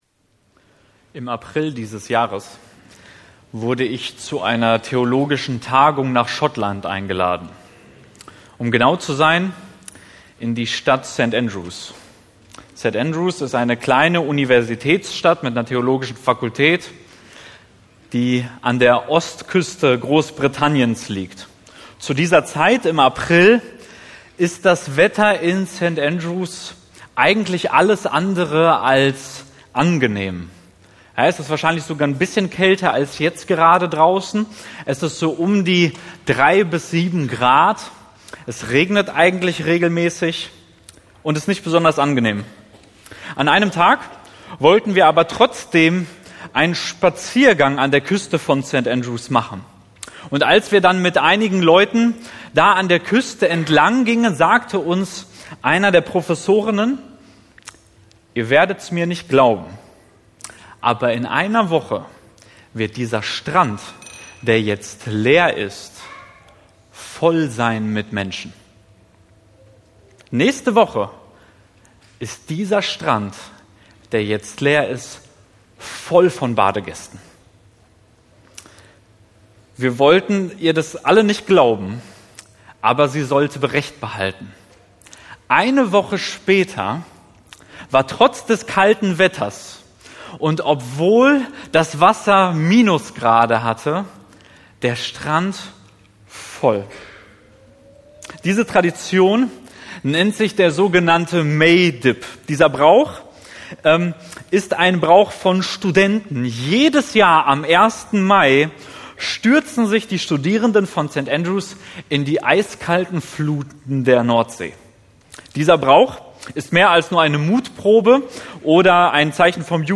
Dezember 2024 Predigt-Reihe